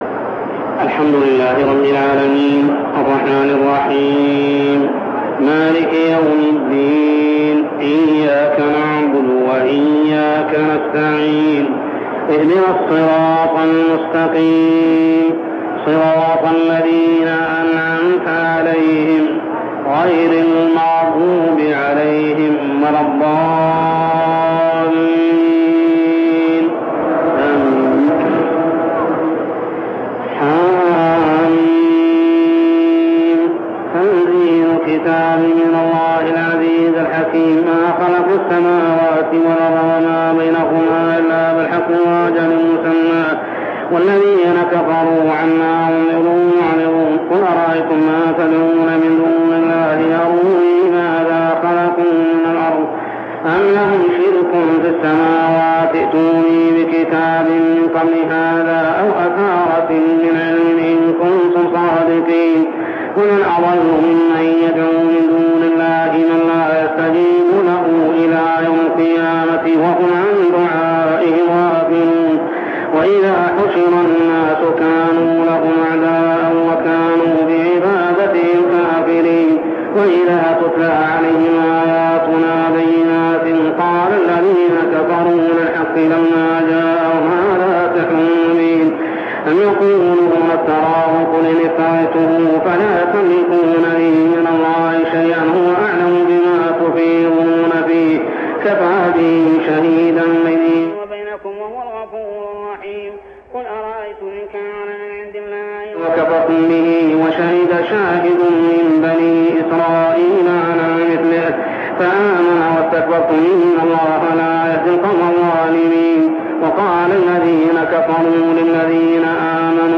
صلاة التراويح عام 1402هـ سور الأحقاف و محمد و الفتح كاملة و الحجرات 1-8 | Tarawih Prayer Surah Al-Ahqaf, Muhammad, Al-Fath, and Al-Hujurat > تراويح الحرم المكي عام 1402 🕋 > التراويح - تلاوات الحرمين